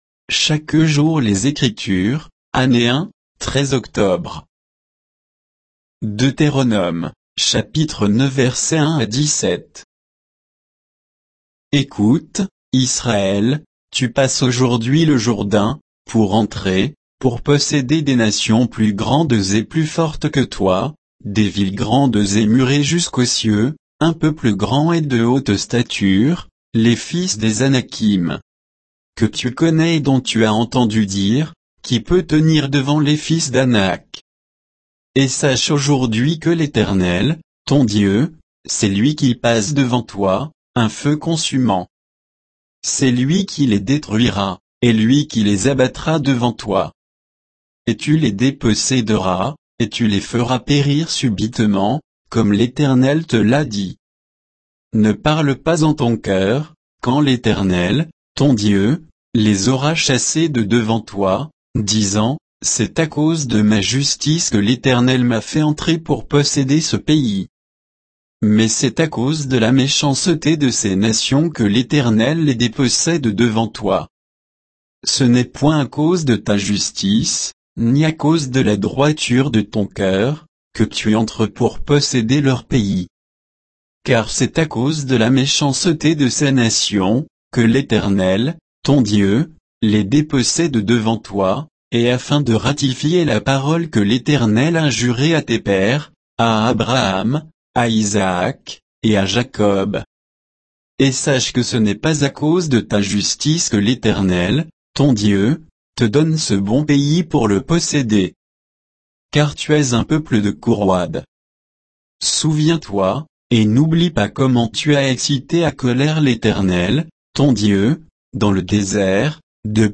Méditation quoditienne de Chaque jour les Écritures sur Deutéronome 9, 1 à 17